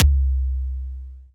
Distort Kick 2.wav